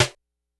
Snares
snr_44.wav